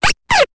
Cri de Scrutella dans Pokémon Épée et Bouclier.